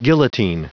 Prononciation du mot guillotine en anglais (fichier audio)
Prononciation du mot : guillotine